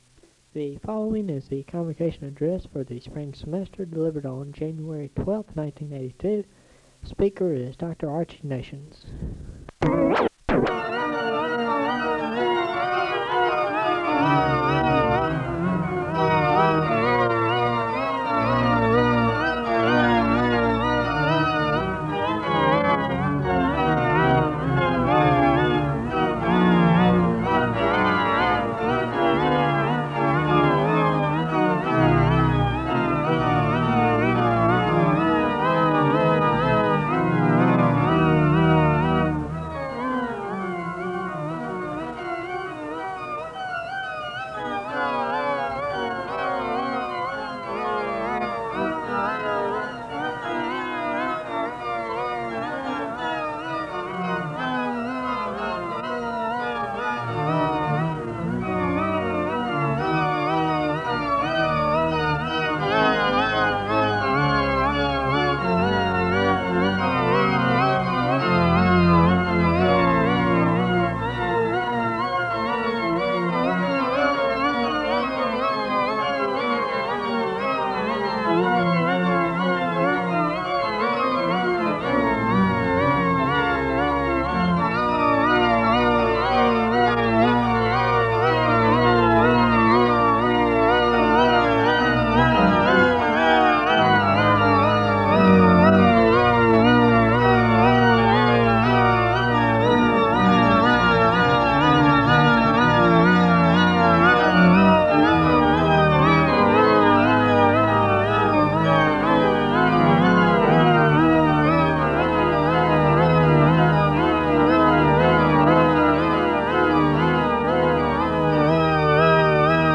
Home SEBTS Convocation